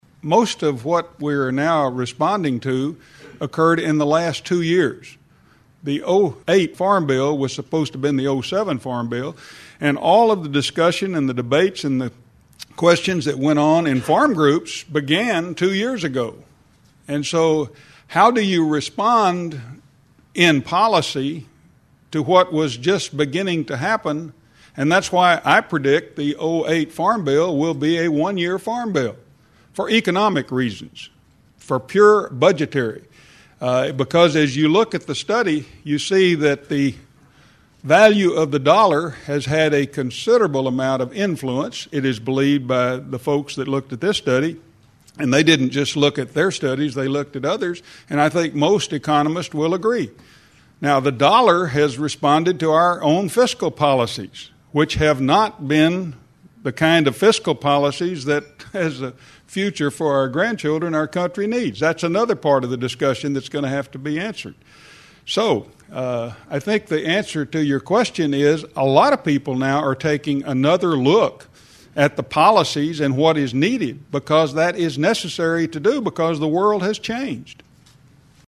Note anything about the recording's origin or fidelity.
• Seven excerpts from the question and answer session: